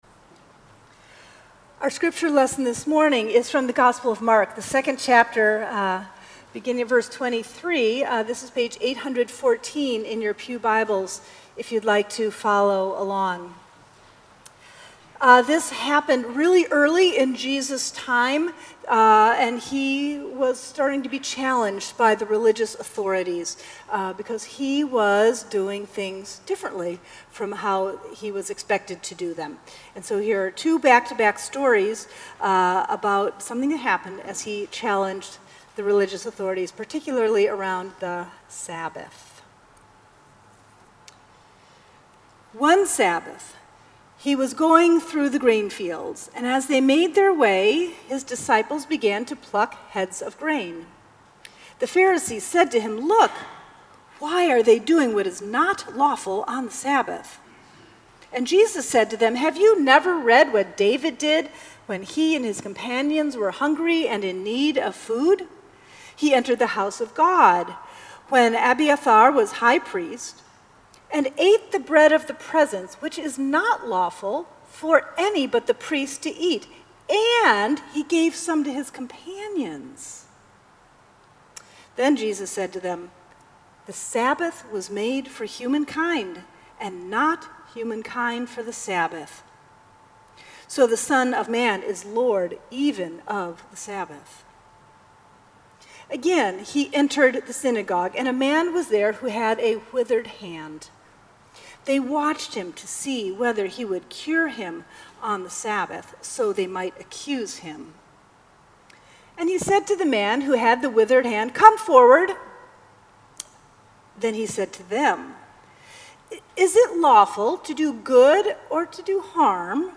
Sermons at Union Congregational Church
June 3, 2018 Second Sunday after Pentecost